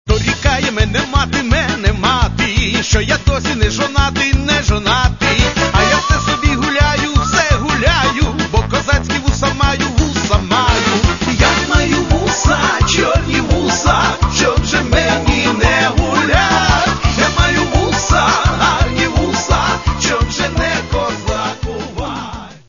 Каталог -> MP3-CD -> Эстрада
Чтобы эти самые развлечения происходили весело и живенько.